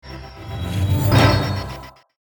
sound_shut_vt.mp3